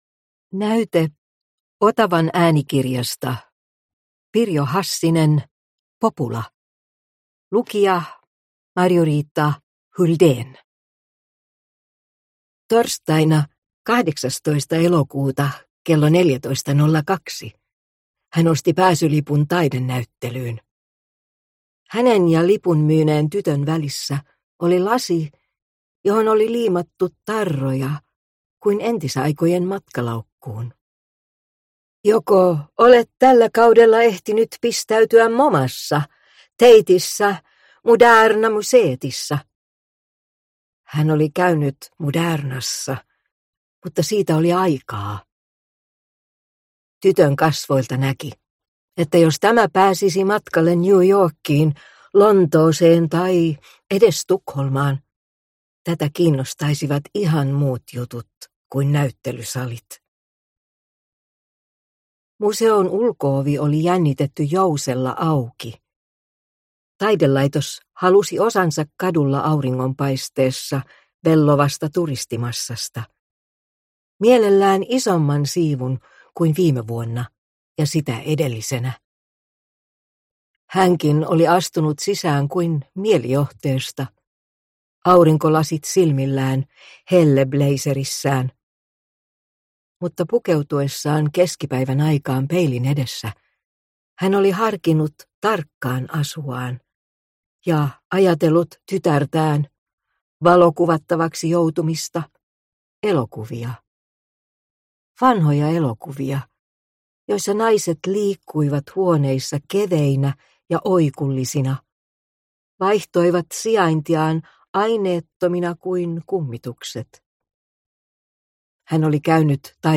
Popula – Ljudbok – Laddas ner